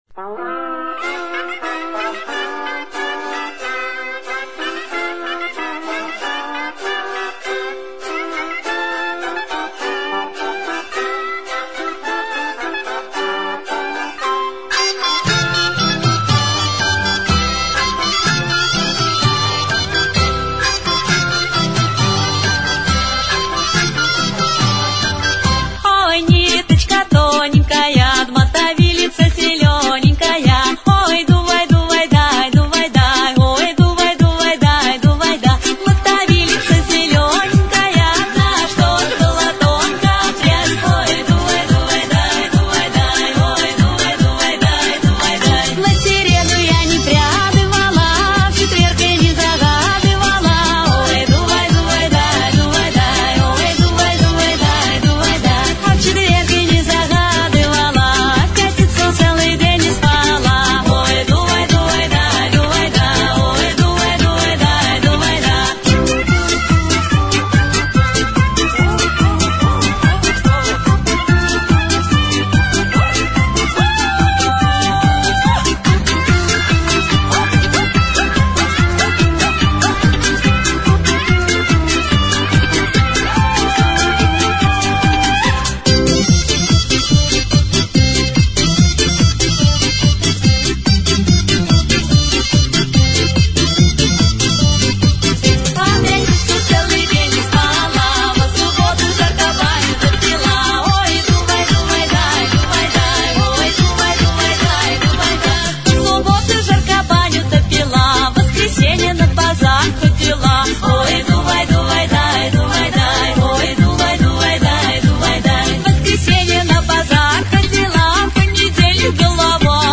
Мы сейчас с вами будем водить задорный хоровод с символом масленицы: солнышком, и у нас будут свои смешные петрушки и скоморохи, которые и будут нас веселить.